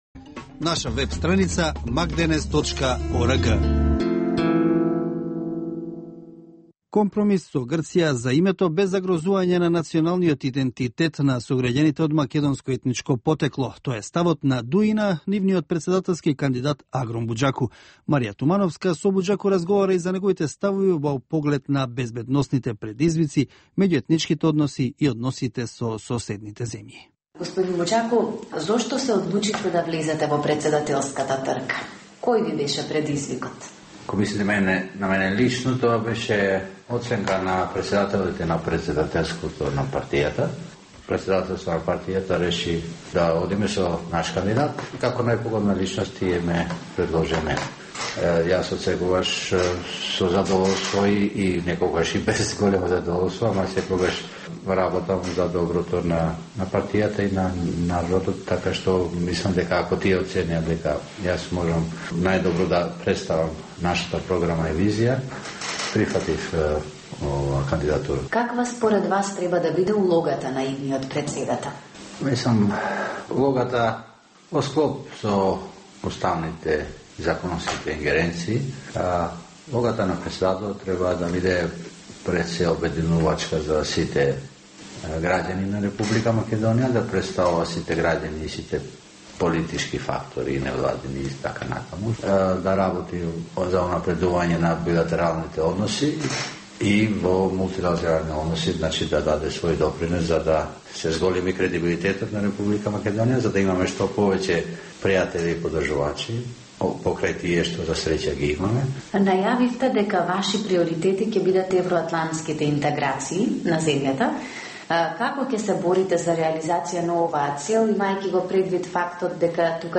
Интервју со претседателскиот кандидат на ДУИ